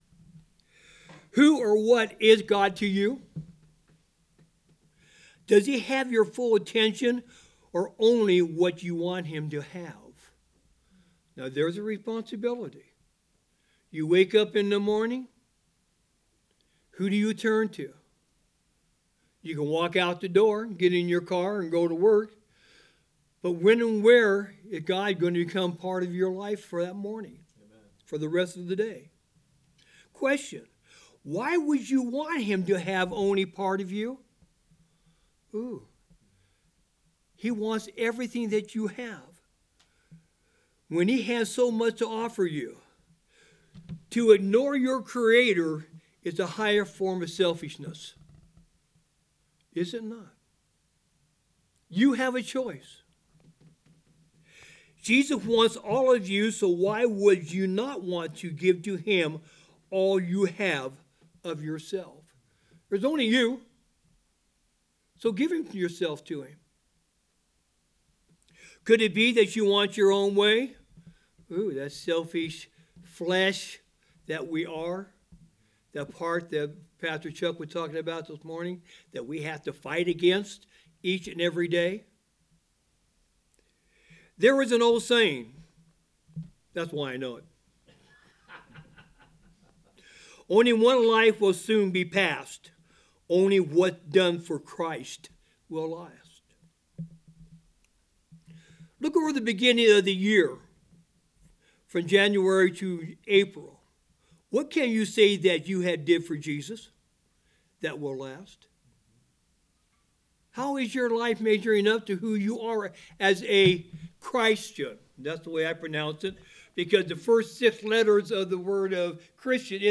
A message from the series "Out of Series."